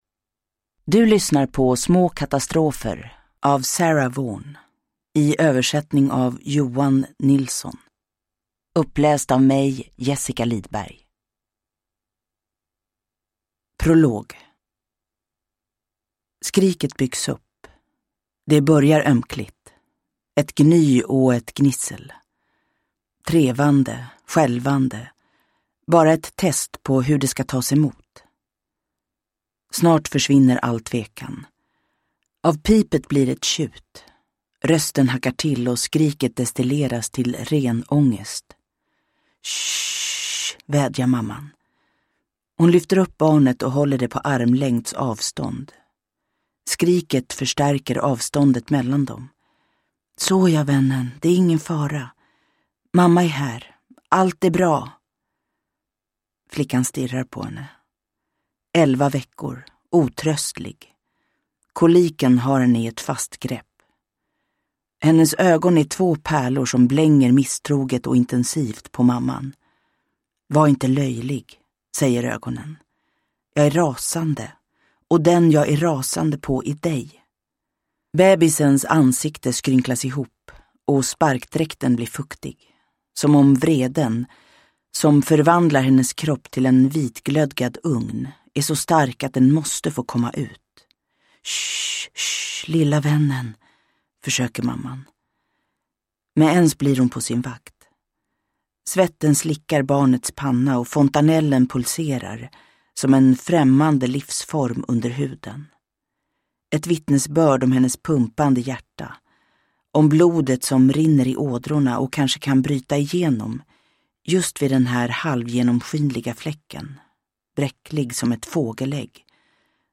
Små katastrofer – Ljudbok – Laddas ner
Uppläsare: Jessica Liedberg